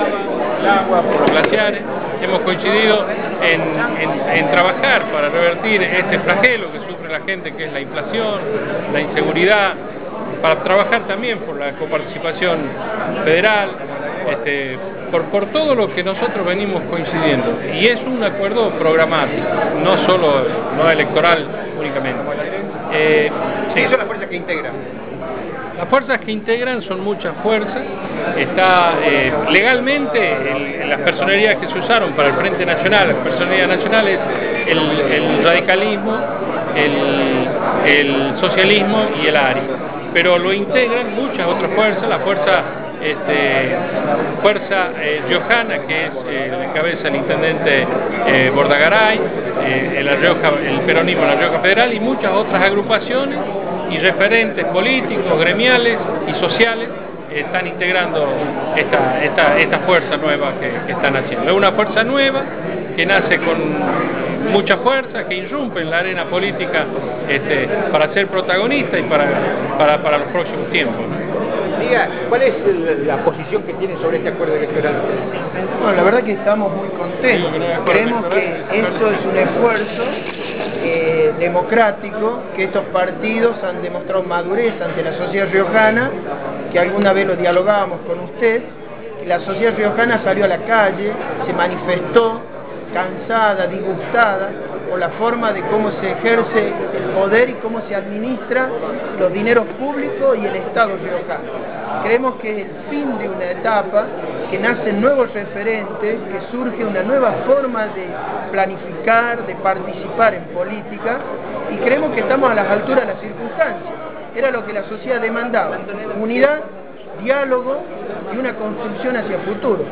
La rueda de prensa de Fuerza Cívica Riojana